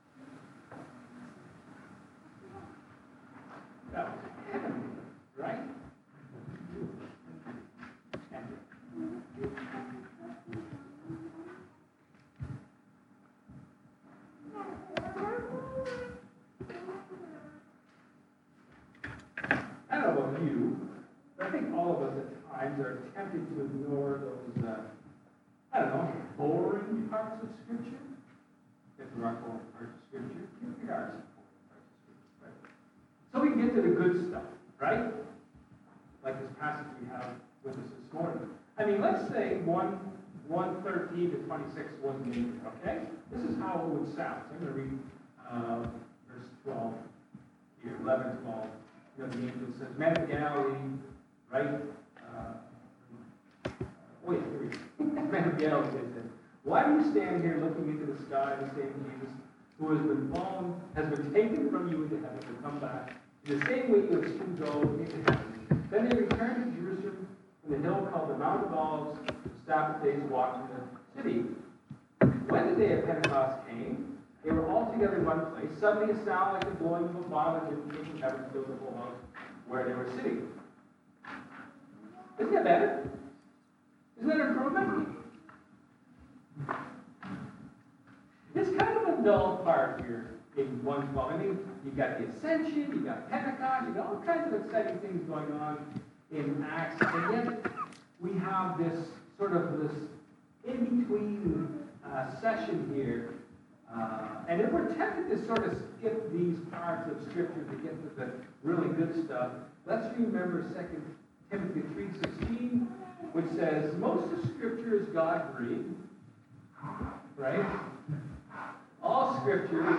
John 4:27-42 Service Type: Sermon